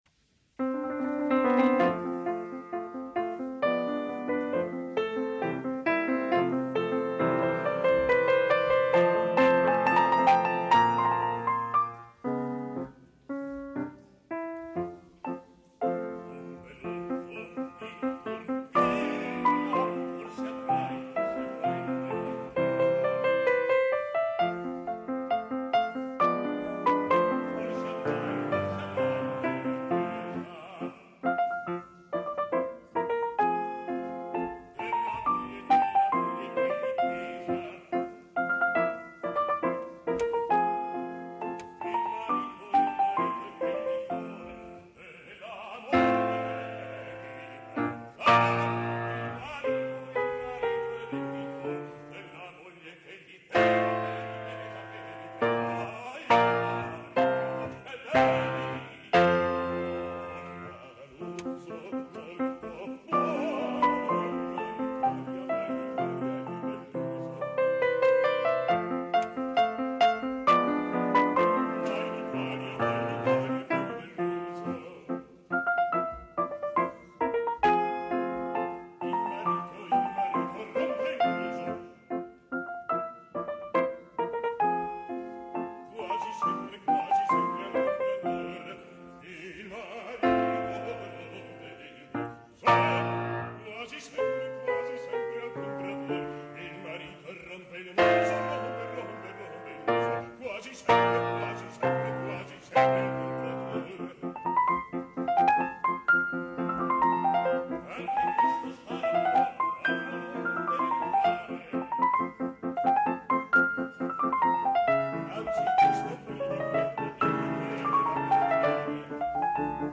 GenereMusica Classica / Cameristica